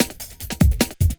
41 LOOPSD1-R.wav